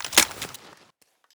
int_clipin.mp3